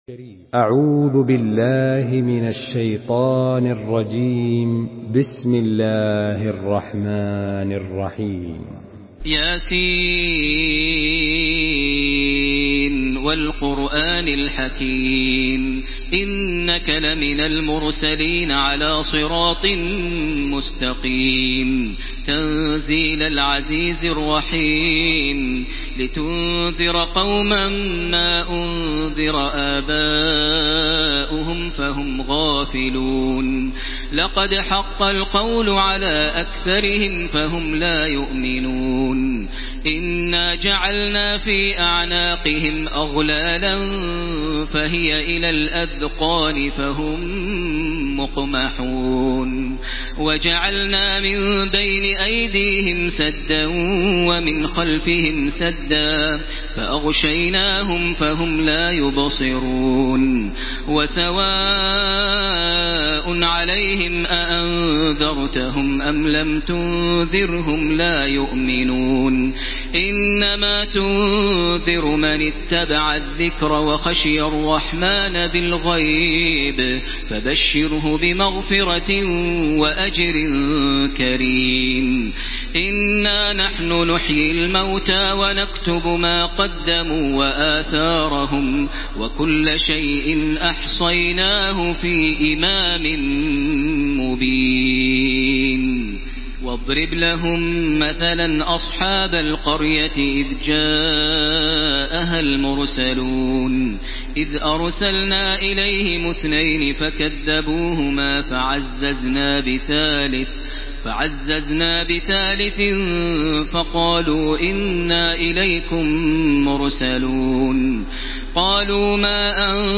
Surah Yasin MP3 by Makkah Taraweeh 1434 in Hafs An Asim narration.
Murattal